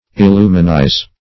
Search Result for " illuminize" : The Collaborative International Dictionary of English v.0.48: Illuminize \Il*lu"mi*nize\, v. t. [imp.